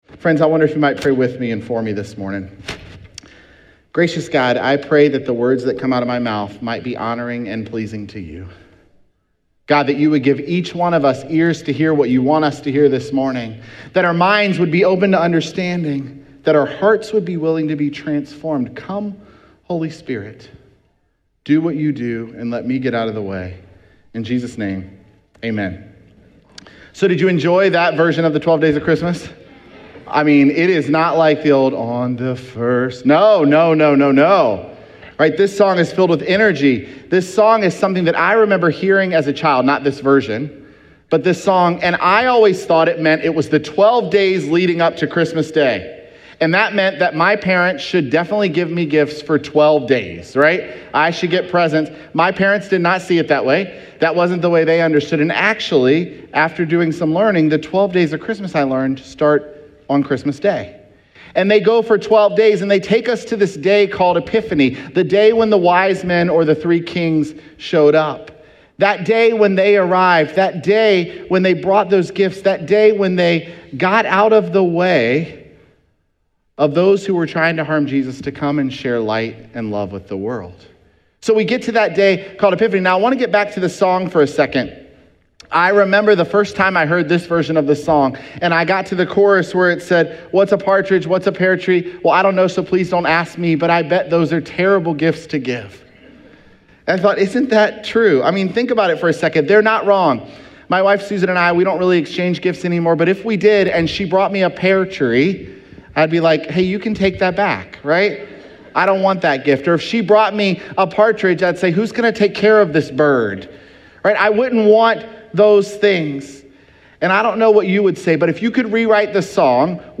Sermons
Dec8SermonPodcast.mp3